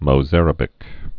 (mō-zărə-bĭk)